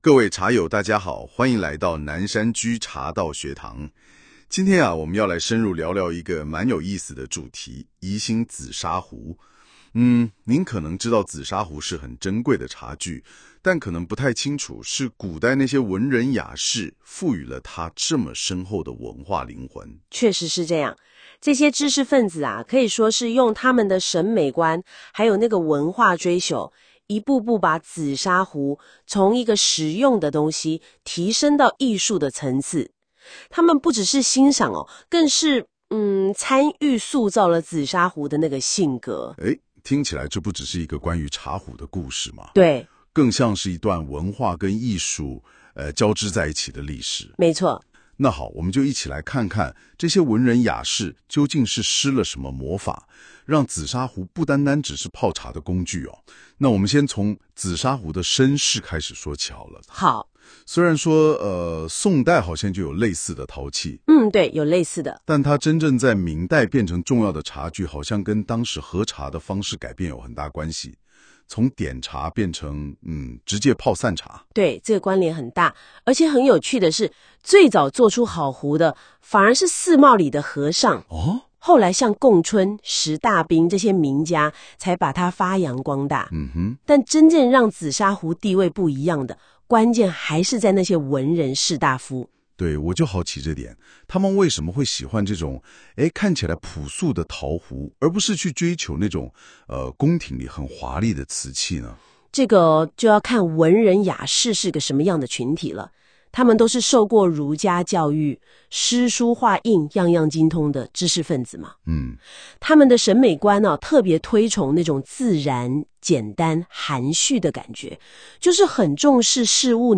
【語音導讀】文人雅士與紫砂壺文化（7分12秒）